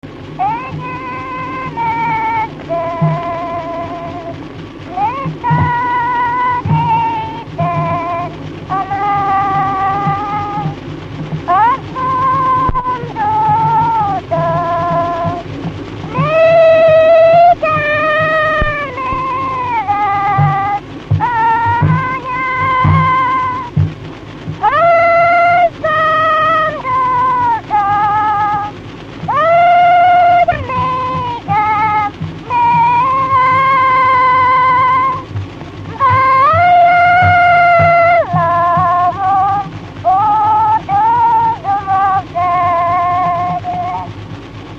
Erdély - Udvarhely vm. - Bözöd
Gyűjtő: Vikár Béla
Stílus: 9. Emelkedő nagyambitusú dallamok